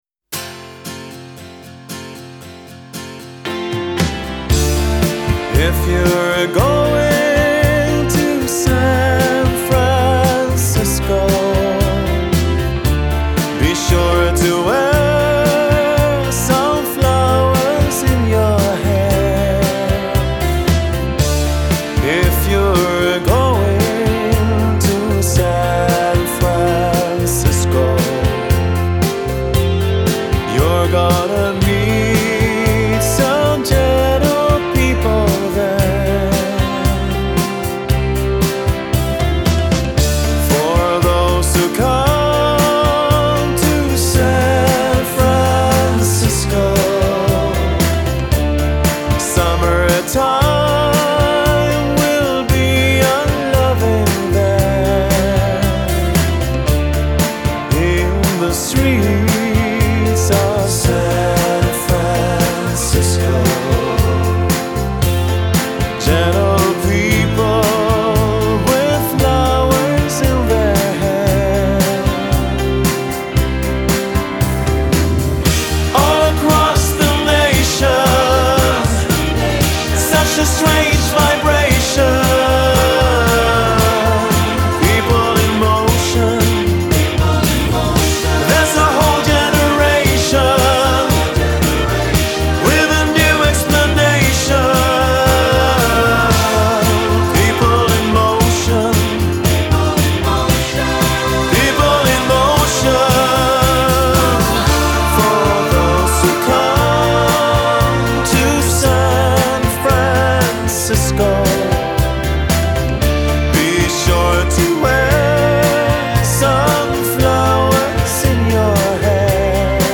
Еще одна хорошая переделка прекрасной старой песни)